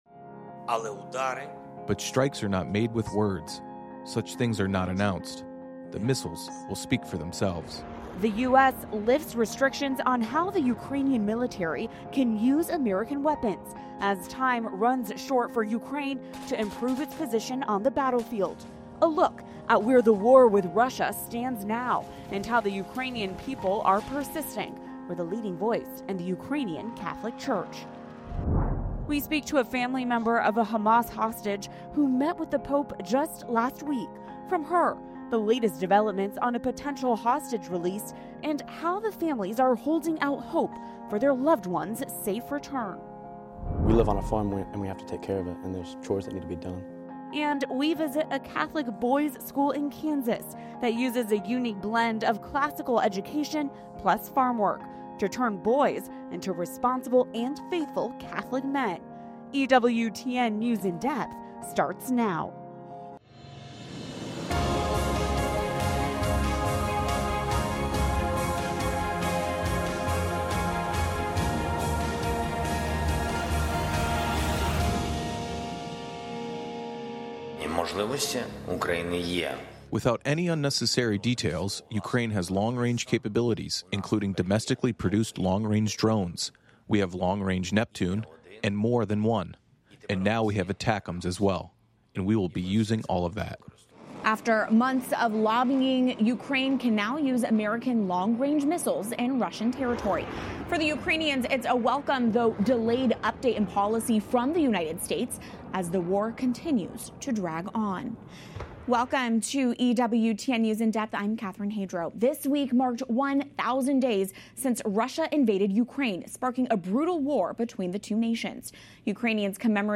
EWTN’s weekly one-hour discussion of current events in the Church, politics, and culture, from a Catholic perspective. Covering the underreported stories and clarifying the Church's position on key teachings, with a focus on the laity's needs and concerns.